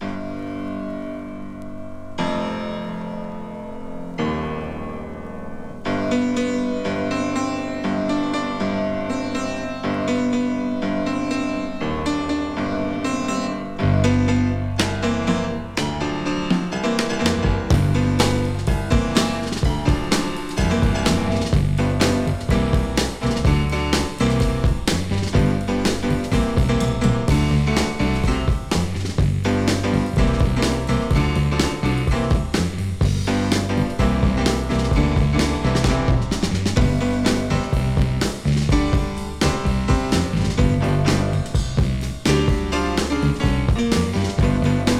大所帯なバンドサウンドに味のあるヴォーカルと鍵盤炸裂。
Rock & Roll, Rock, Pop　USA　12inchレコード　33rpm　Stereo